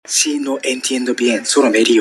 solo me rio Meme Sound Effect
This sound is perfect for adding humor, surprise, or dramatic timing to your content.